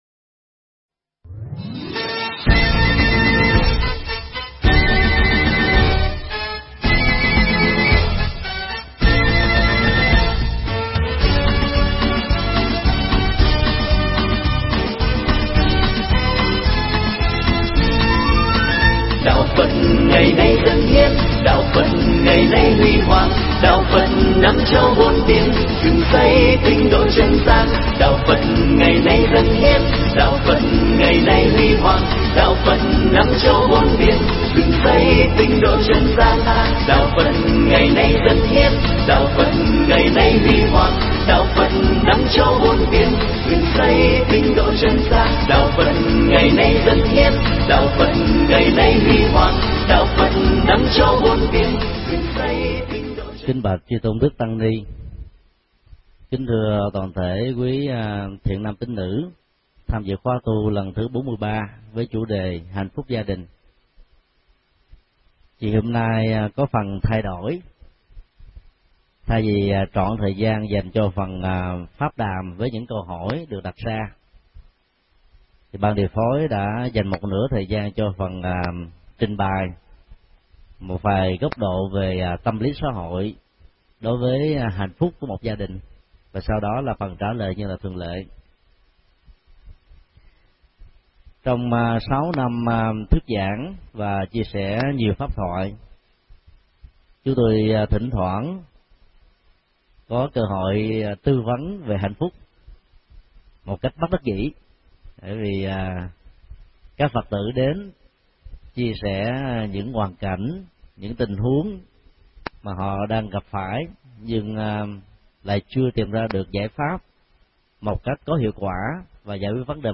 Mp3 Pháp âm Hạnh phúc gia đình được thầy Thích Nhật Từ thuyết giảng tại Chùa Phổ Quang, ngày 19 tháng 10 năm 2008